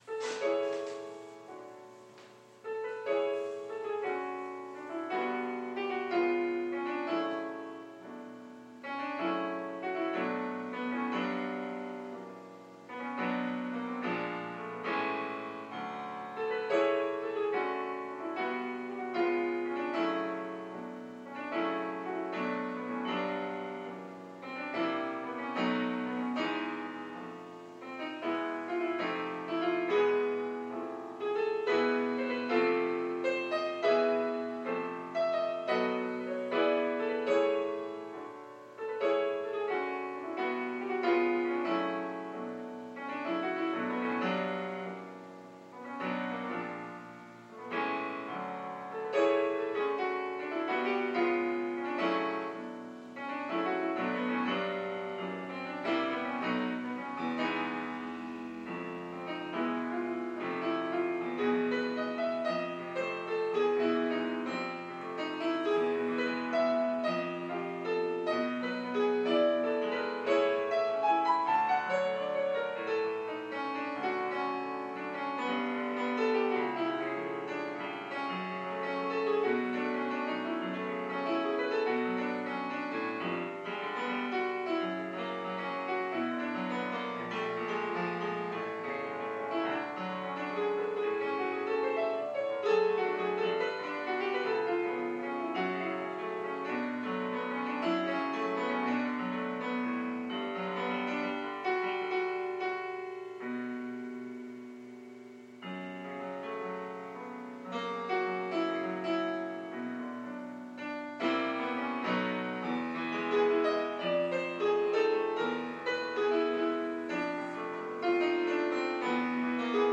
Op. 8 Jazz Lead Sheet: "In Your Eyes"